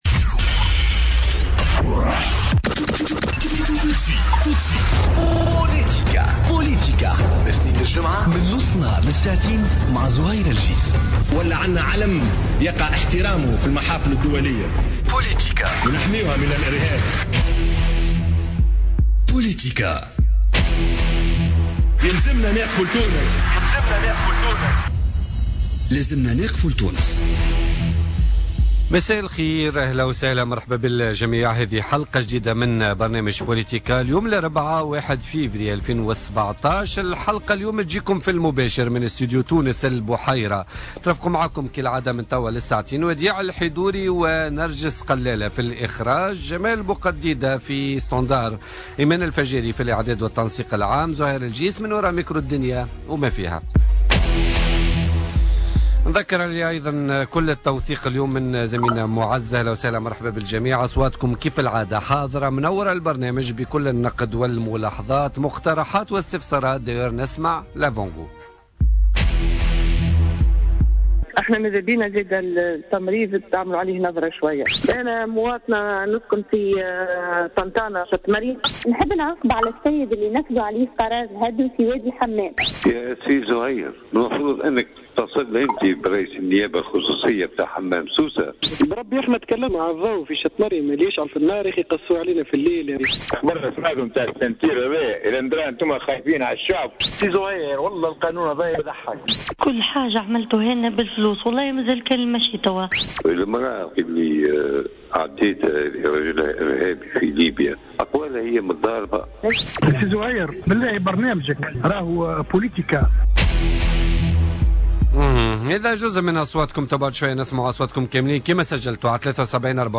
Selma Elloumi Rekik, ministre du Tourisme et de l'Artisanat, invitée de politica